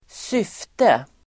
Uttal: [²s'yf:te]